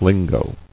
[lin·go]